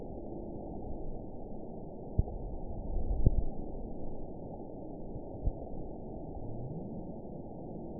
event 920335 date 03/17/24 time 01:54:42 GMT (1 year, 1 month ago) score 9.41 location TSS-AB01 detected by nrw target species NRW annotations +NRW Spectrogram: Frequency (kHz) vs. Time (s) audio not available .wav